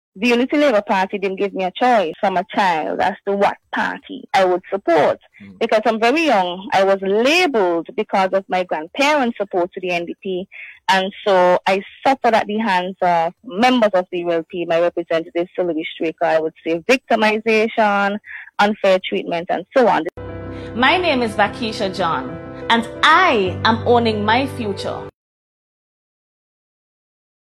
In the recording, which appears to come from an interview with a local radio station